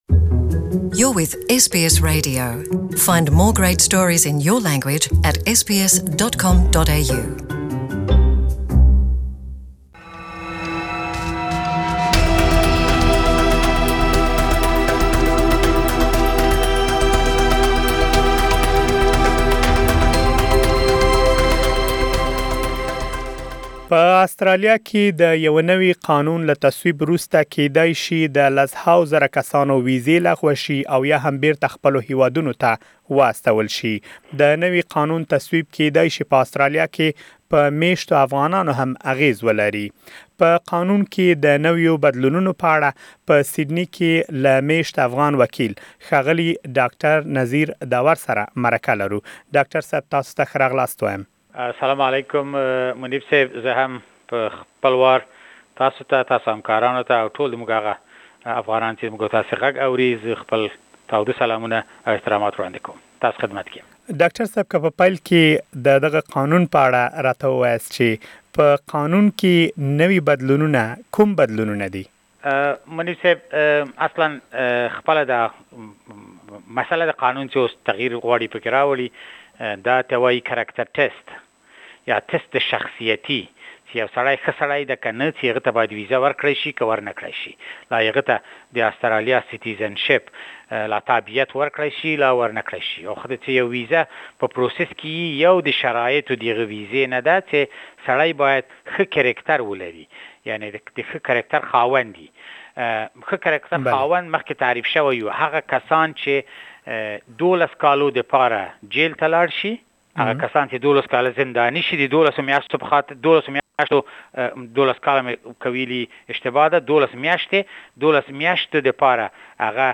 For more details, Please listen to the full interview in Pashto language with solicitor